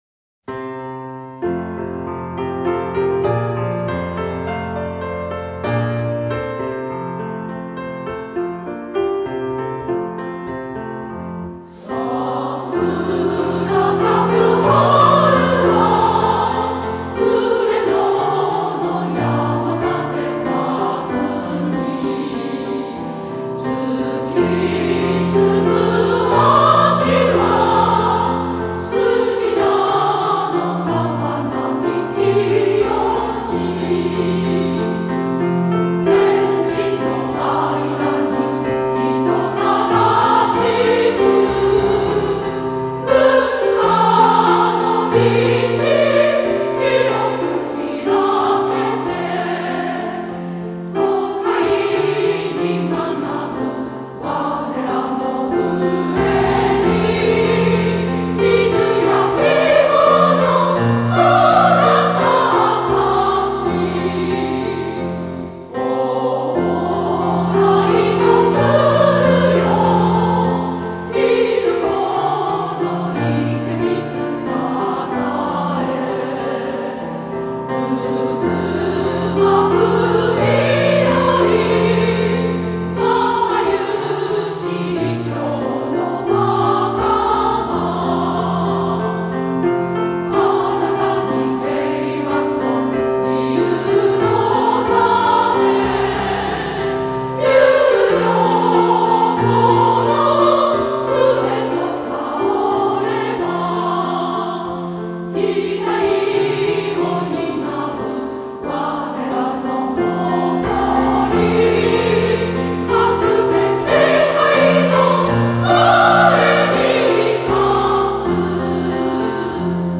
校歌
歌・演奏：千鶯会